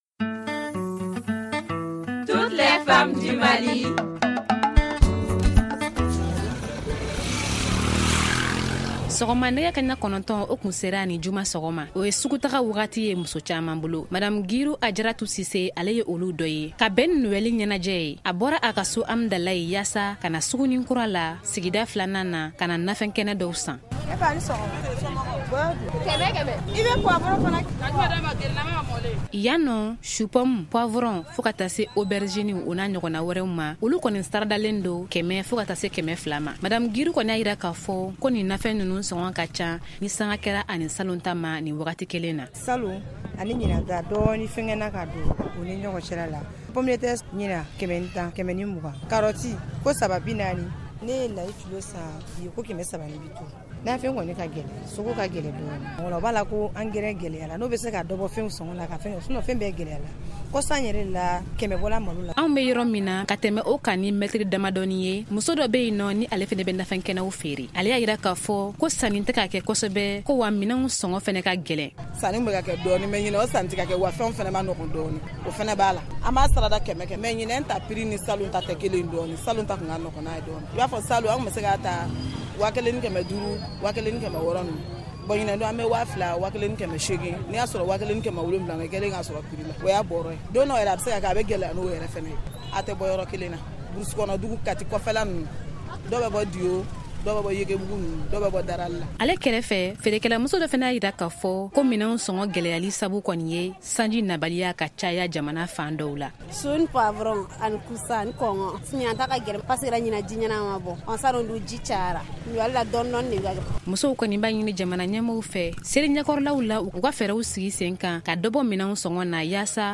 Magazine en bambara: Télécharger